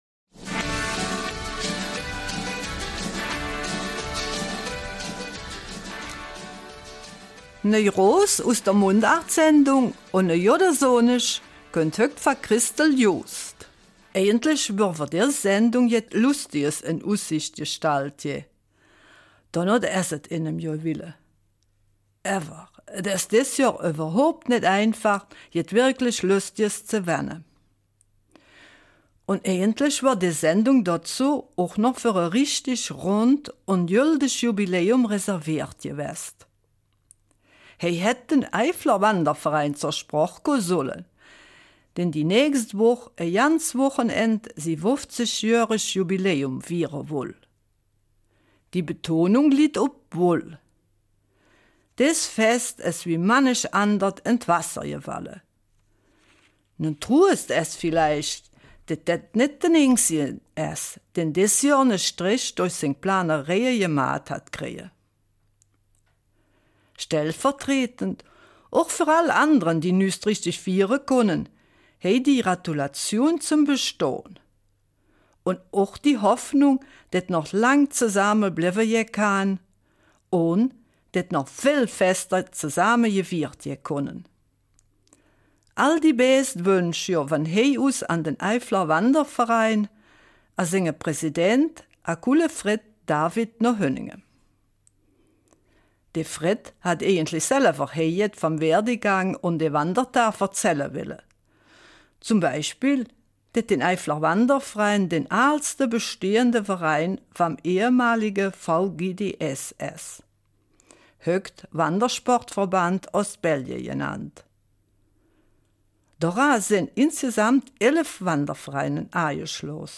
Eifeler Mundart: Wanderungen und ungefeierte Feste